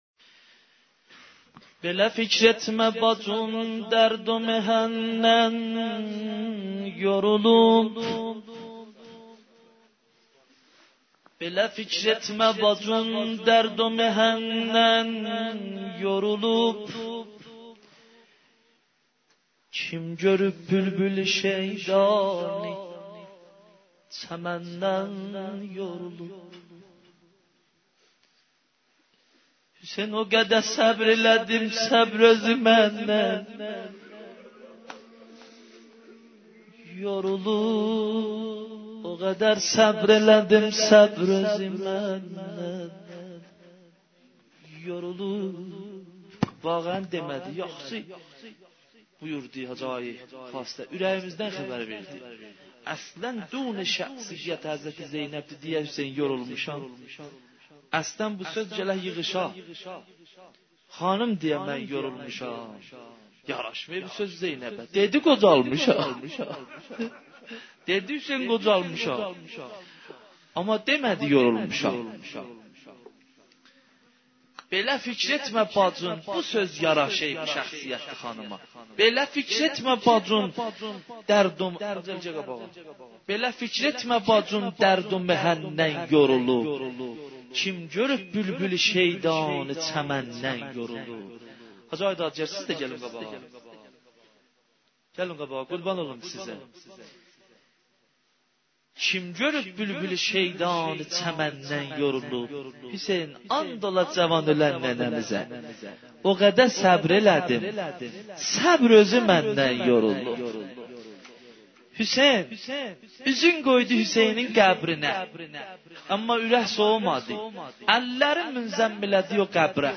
مداحی ترکی محرم